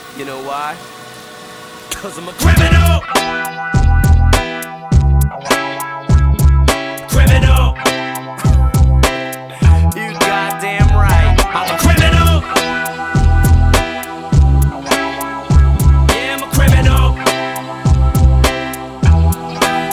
I managed to locate my Giant Squid Audio Lab binaural microphones and the same pair of sunglasses to which I normally clipped them, and did a test recording with my Zoom H1 Essential. In it, I perform the usual stupid tasks like sticking my head in the freezer, opening and closing doors, and later take the dog outside for a brief walk and demonstrate our windchimes out back.
Some normalization was added which squished the louder dynamics a bit.